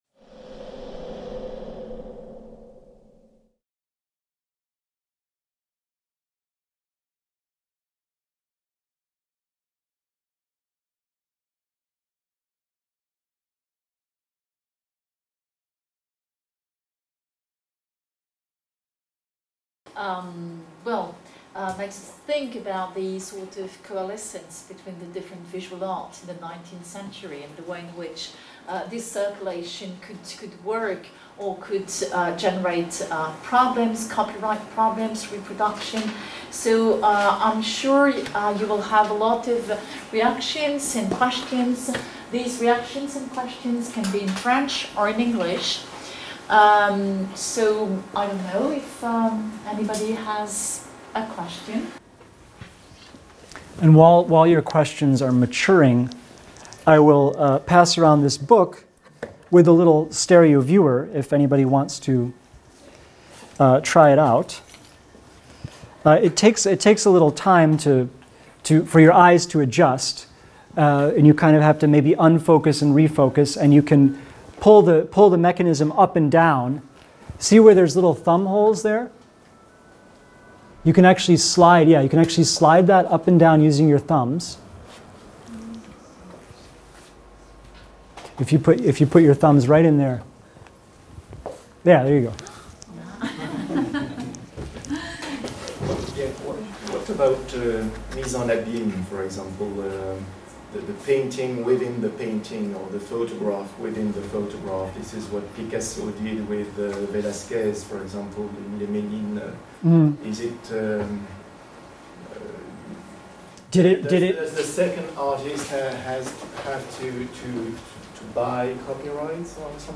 Artistic Culture and Intellectual Property in the Nineteenth Century (2021)” - Discussion | Canal U